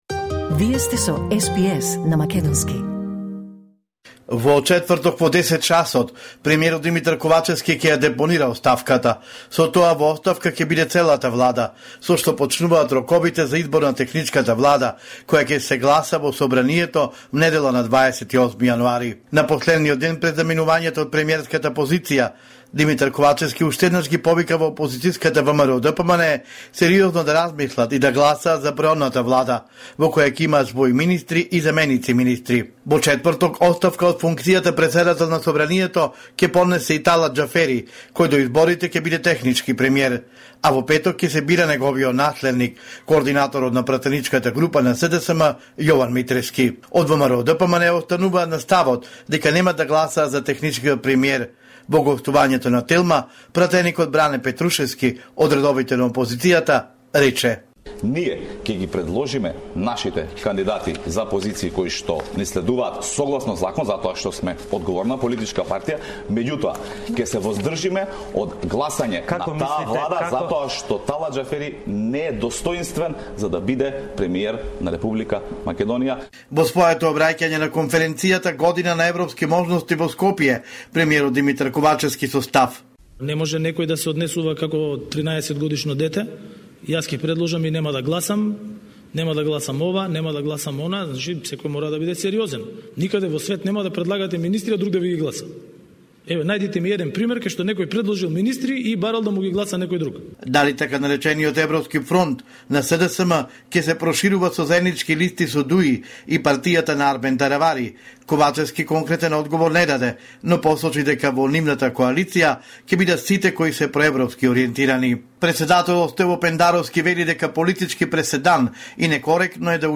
Homeland Report in Macedonian 24 January 2024